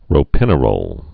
(rō-pĭnə-rōl)